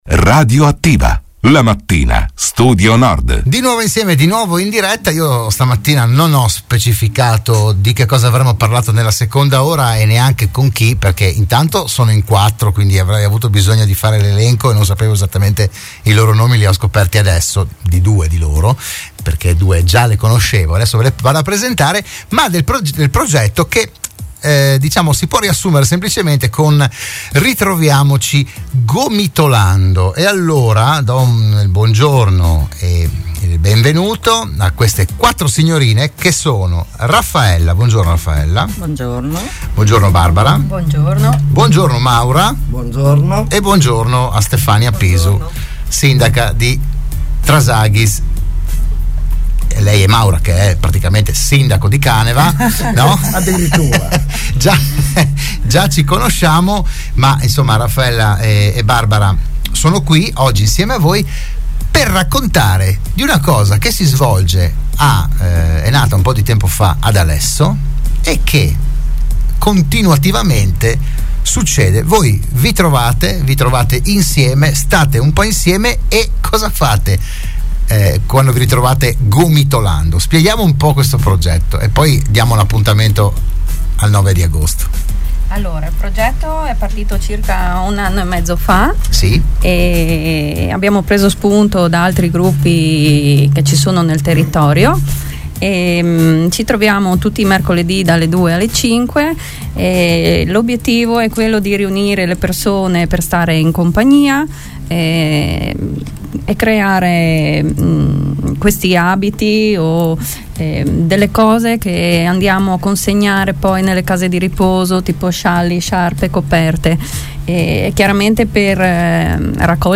Dell'iniziativa si è parlato nella trasmissione "RadioAttiva" di Radio Studio Nord